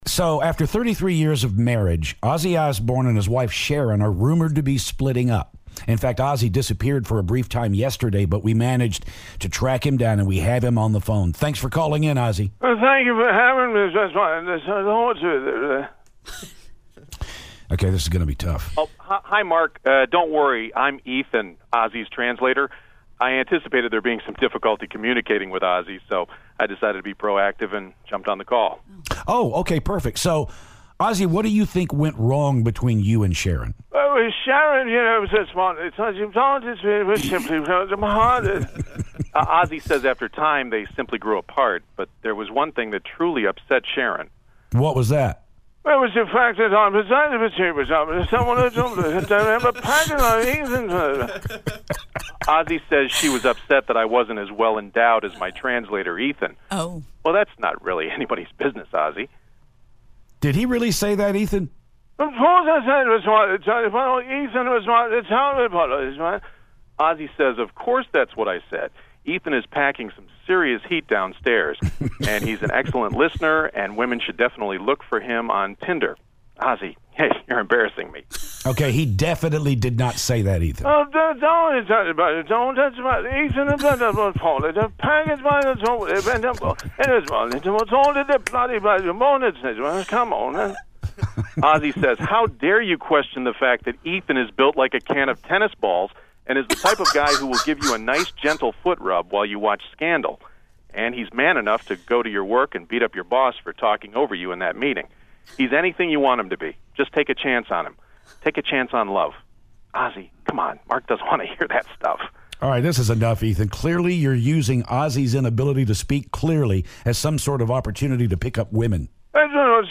Ozzy Osbourne Phoner
Ozzy Osbourne calls to talk about his breakup with Sharon.